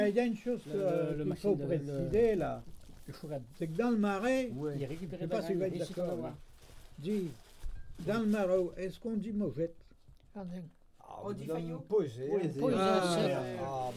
Langue Maraîchin
Atelier de collectage de témoignages sur les légumes traditionnels du Marais-Breton-Vendéen
Catégorie Témoignage